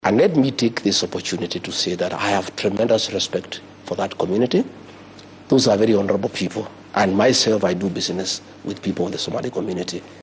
Madaxweyne ku xigeenkii hore ee dalka Rigathi Gachagua oo xalay waraysi gaar ah siinayay telefishanka maxaliga ah ee Citizen ayaa beeniyay eedeyn uga timid madaxda Mandera.